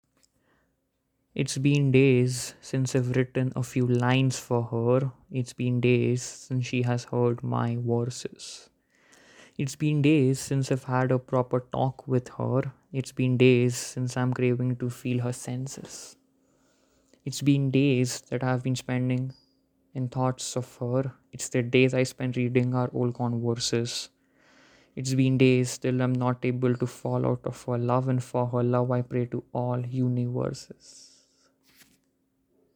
As you know the drill, I always prefer to recite my pieces and hence, would request you to download/play the audio, simultaneously while reading the lyrics, for the best experience.